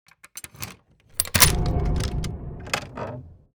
Wood_Key.ogg